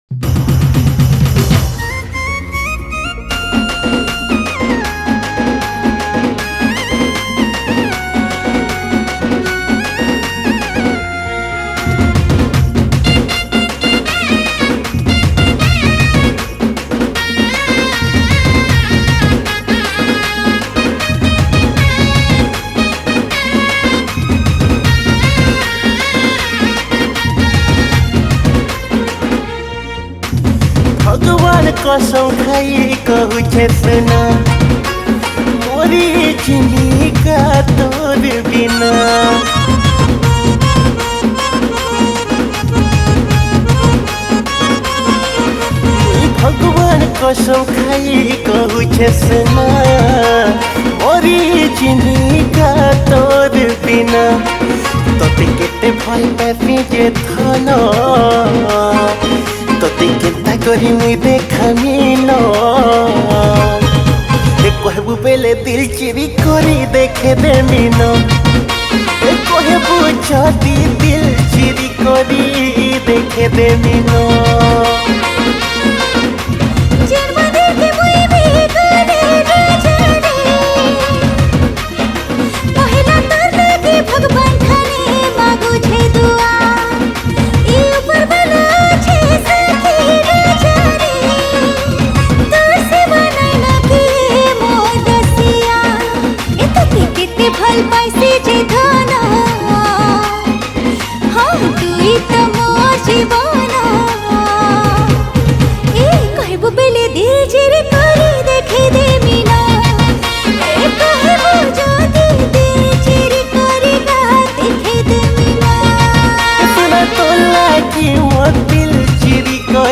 New Sambalpuri Song 2026